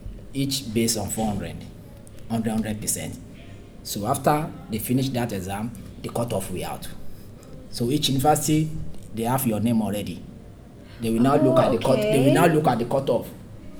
S1 = Bruneian female S3 = Nigerian male
There is little aspiration on the [k] in cut (VOT = 31 msec), and the vowel in cut is identical to that in off , which explains why it is heard as got instead of cut . In addition, the [l] at the end of will is pronounced as an approximant [j] rather than the linking [l] that might be expected in the middle of will out , and this is why S1 transcribed it as way out .